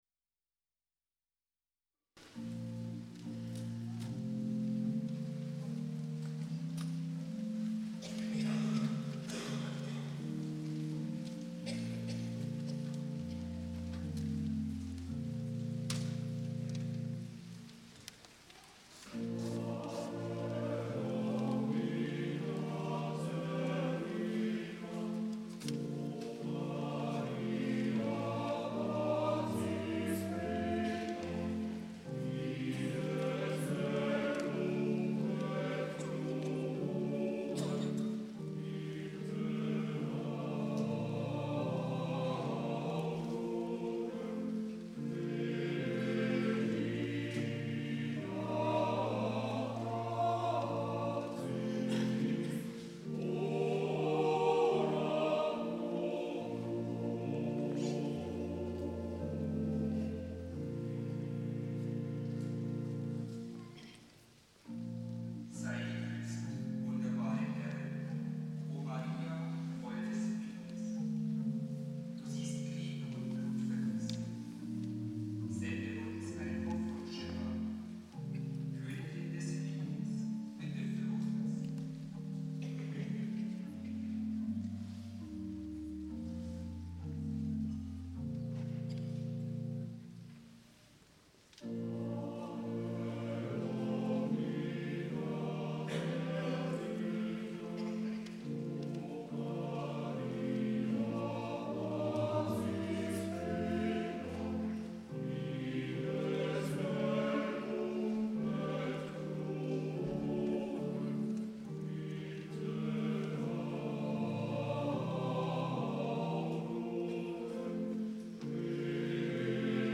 P. Gregor Schwake OSB komponierte im KZ Dachau die Dachauer Messe als Missa antiphonaria mit Blechbläsern und widmete sie Bischof Gabriel Piguet.
Dachauer-Messe aus der Kirche Heilig Kreuz in Dachau am 19.